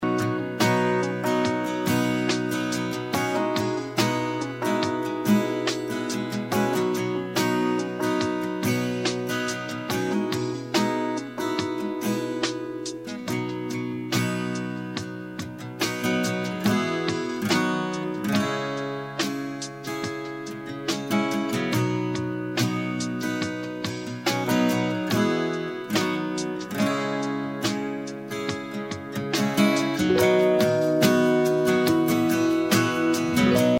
B/Trax – Medium Key without Backing Vocals